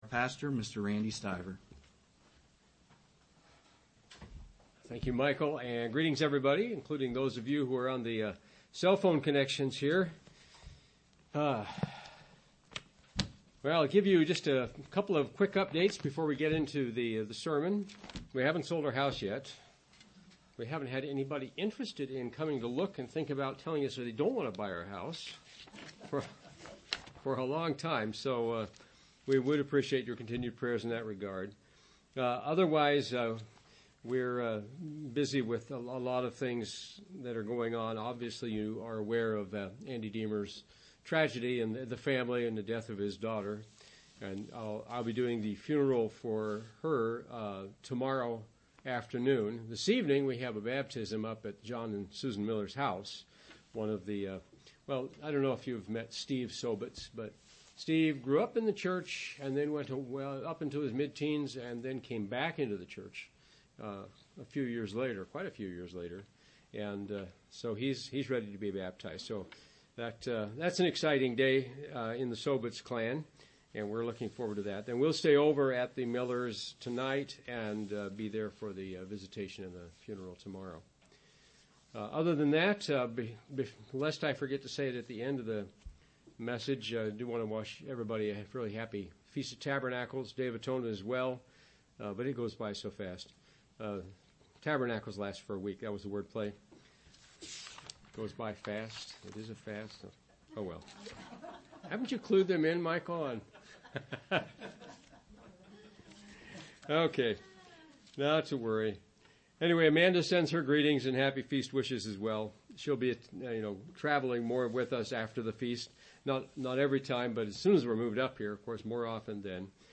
Given in Wheeling, WV
UCG Sermon Studying the bible?